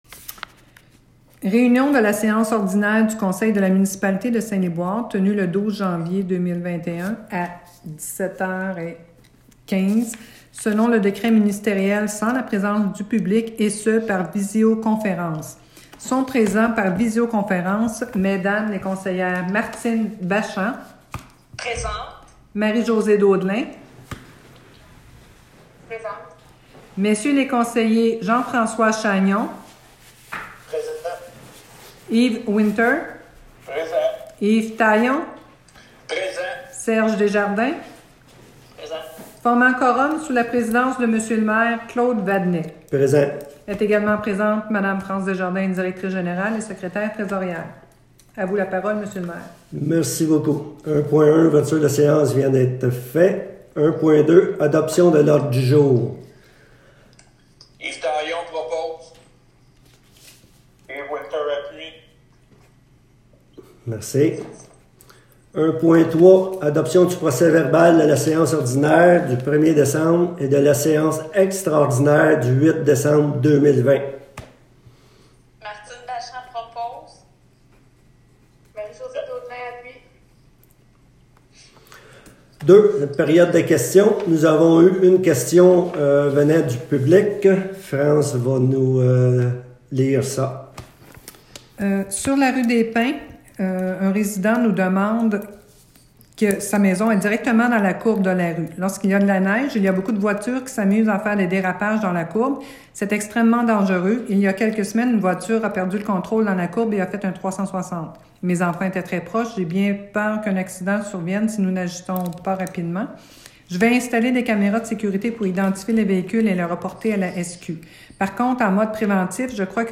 Séances du conseil - Municipalité de Saint-Liboire